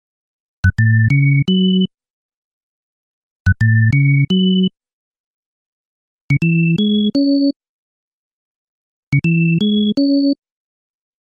嘻哈罗兹风琴
描述：用Massive制作的小旋律
标签： 85 bpm Hip Hop Loops Organ Loops 1.90 MB wav Key : Unknown
声道立体声